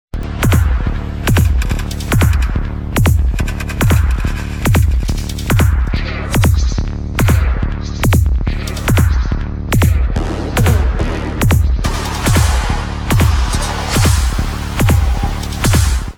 techno-20-pv.wav